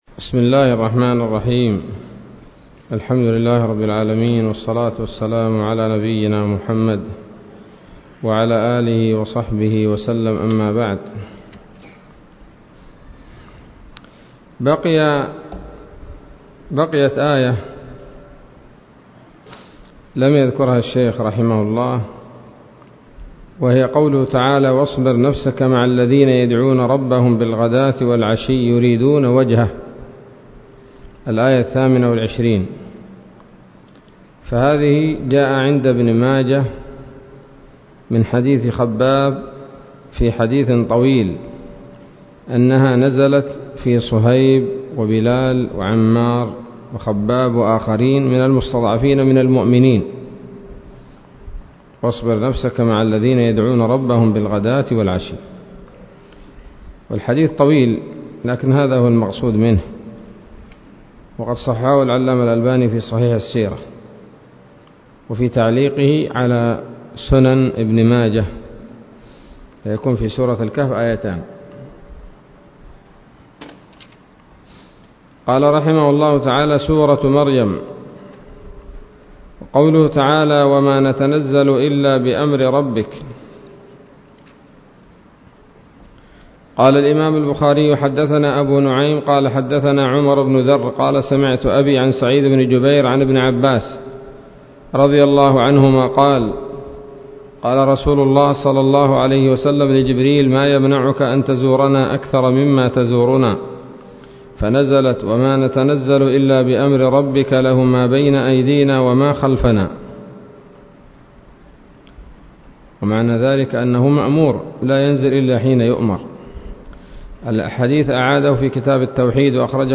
الدرس الخمسون من الصحيح المسند من أسباب النزول